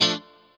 CHORD 2   AA.wav